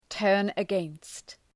turn-against.mp3